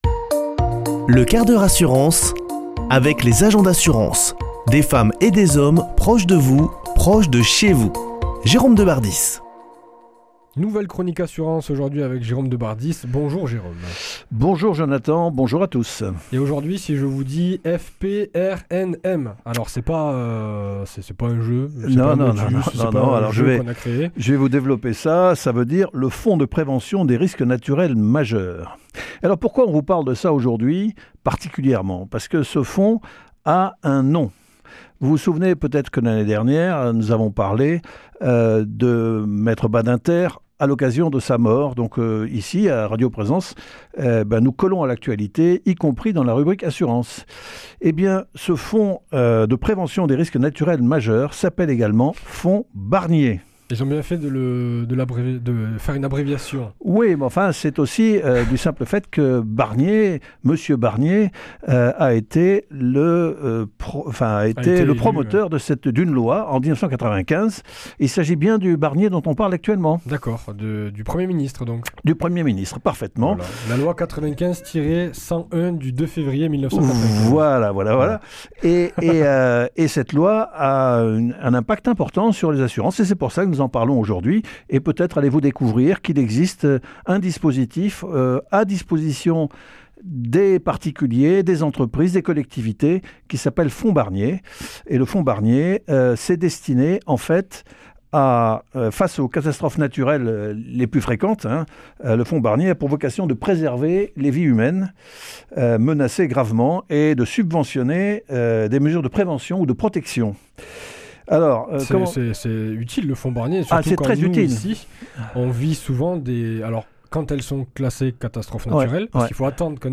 mardi 17 septembre 2024 Chronique le 1/4 h assurance Durée 5 min
Une émission présentée par